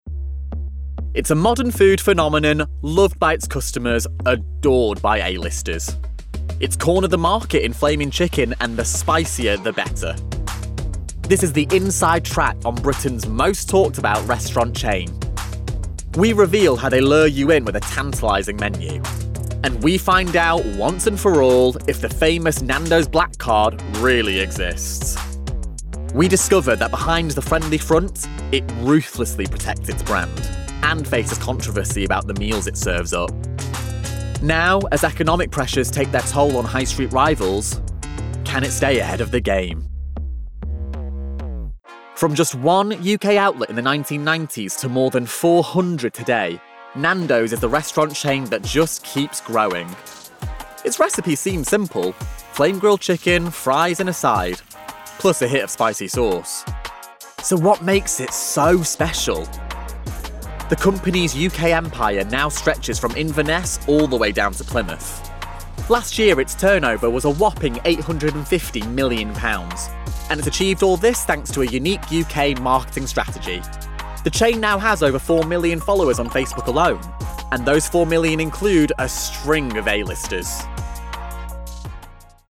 Voice Reel
TV Narration